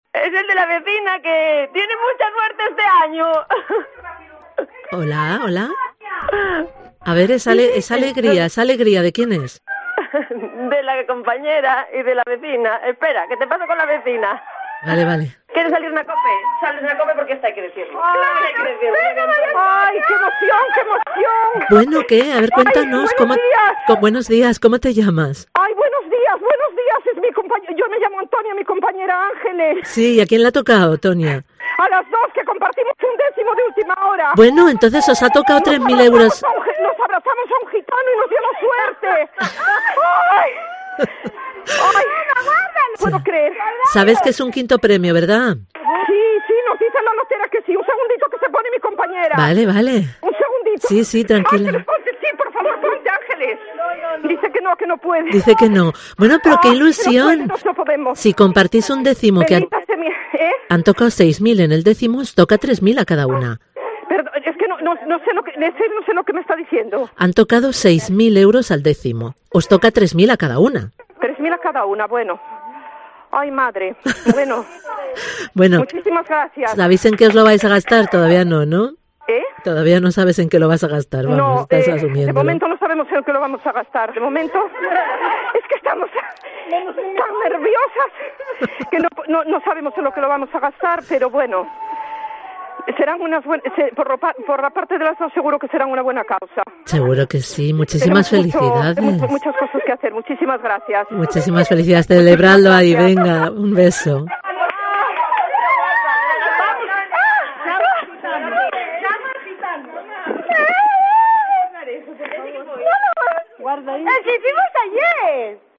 El premio es modesto pero estaban contentísimas, como puedes escuchar.